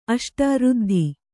♪ aṣta řddhi